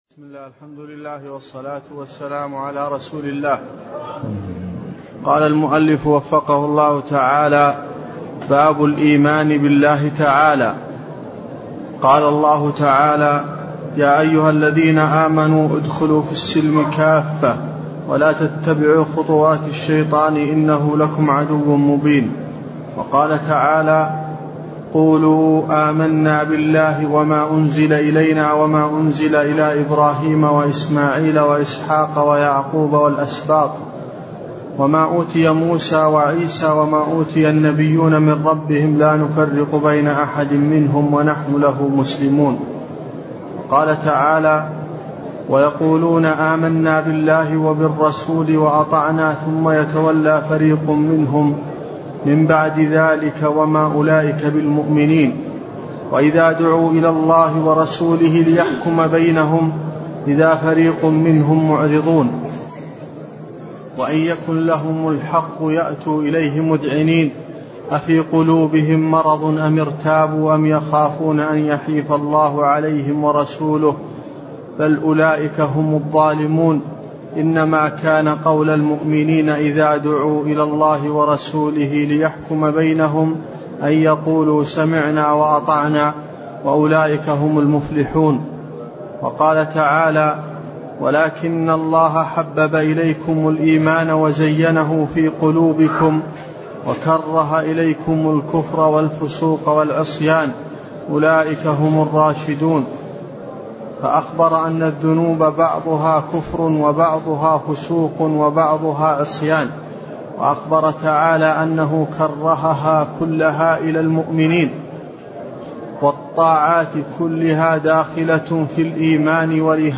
تفاصيل المادة عنوان المادة الدرس (4) شرح المنهج الصحيح تاريخ التحميل الأحد 15 يناير 2023 مـ حجم المادة 30.99 ميجا بايت عدد الزيارات 315 زيارة عدد مرات الحفظ 108 مرة إستماع المادة حفظ المادة اضف تعليقك أرسل لصديق